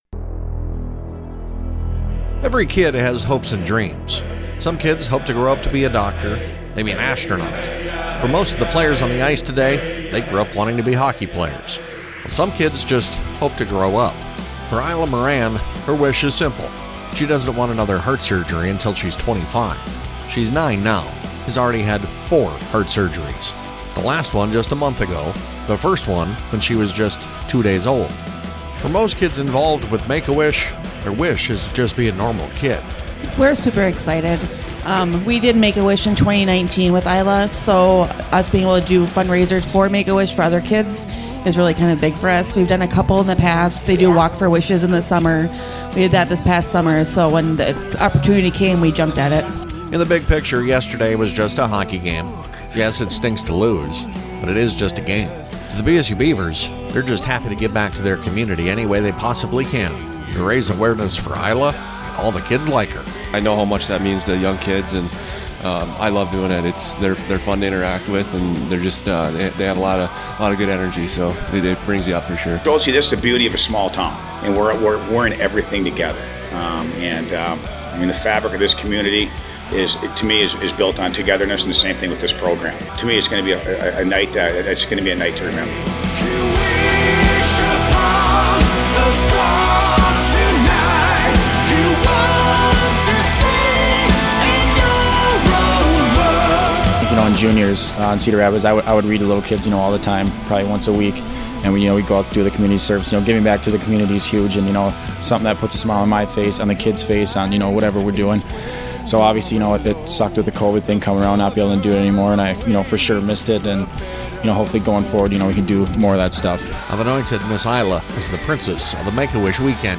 Pregame Open